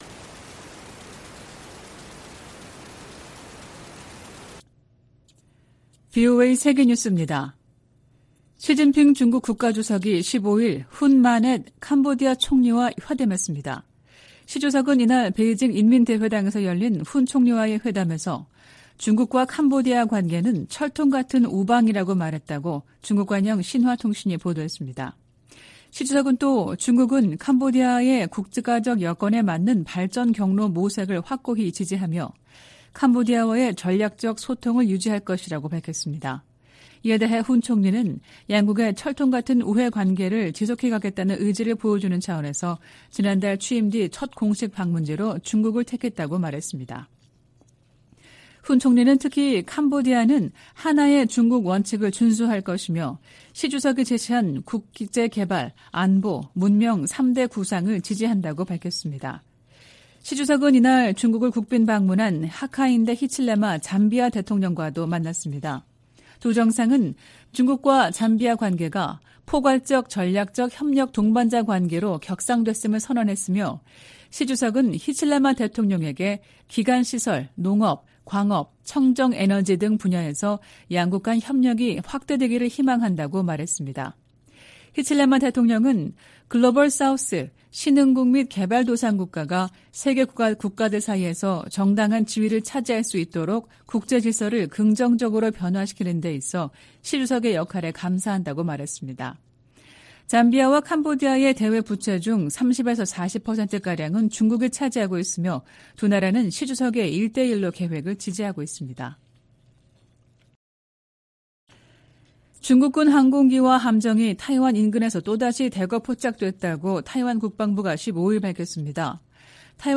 VOA 한국어 '출발 뉴스 쇼', 2023년 9월 16일 방송입니다. 미국과 한국은 유엔 안보리 결의에 부합하지 않는 북한과 러시아 간 협력에 강력한 우려를 표명한다고 밝혔습니다. 미 국방부는 북한과 러시아 간 추가 무기 거래 가능성과 관련, 민간인 학살에 사용되는 무기를 제공해선 안 된다고 거듭 강조했습니다. 미국 정부가 한국에 대한 50억 달러 상당 F-35 스텔스 전투기 25대 판매를 승인했습니다.